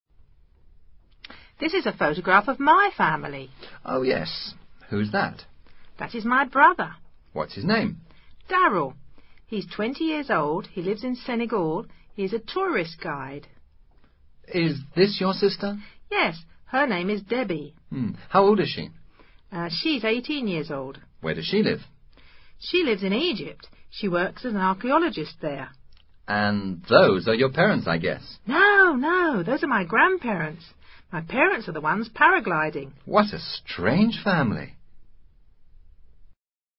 Diálogo en el cual dos personajes describen a los miembros de sus familias.